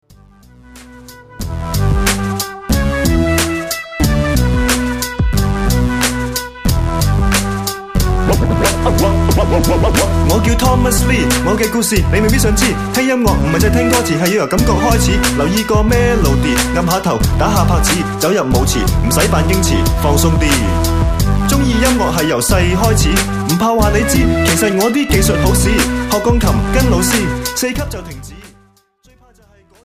hip hop style mixing with rock and folk